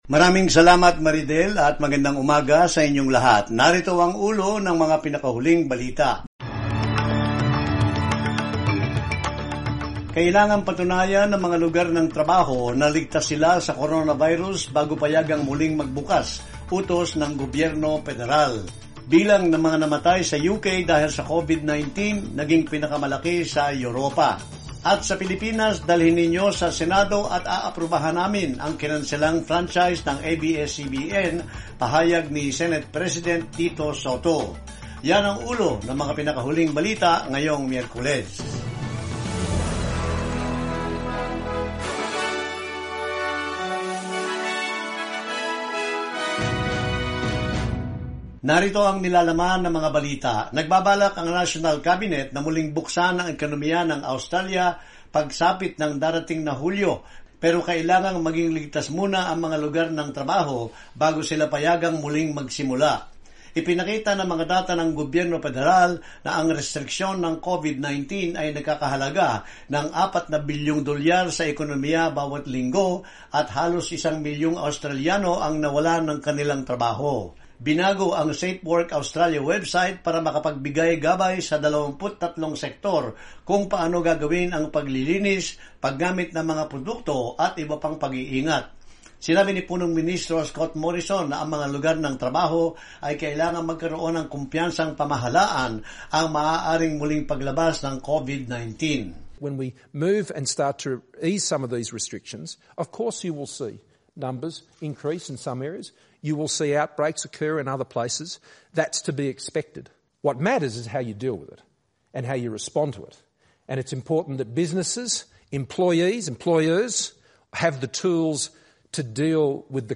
morning_news_0605_final.mp3